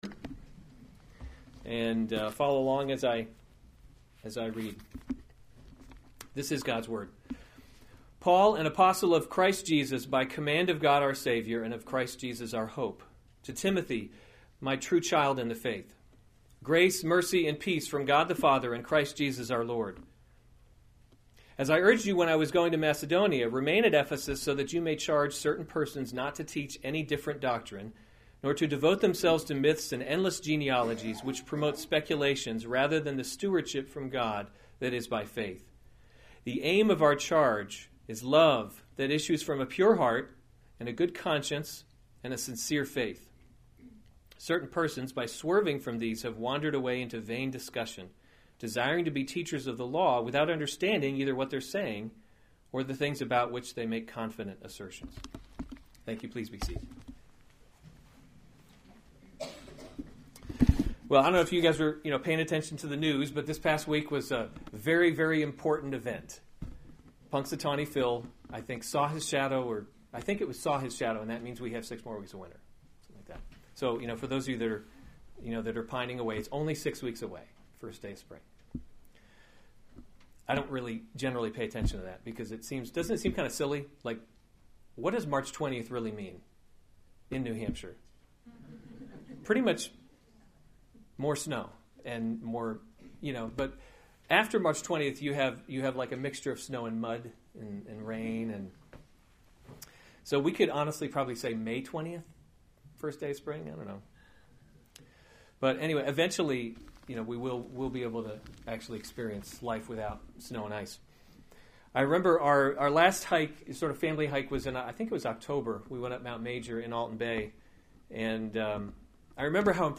February 4, 2017 1 Timothy – Leading by Example series Weekly Sunday Service Save/Download this sermon 1 Timothy 1:1-7 Other sermons from 1 Timothy Greeting 1:1 Paul, an apostle of Christ […]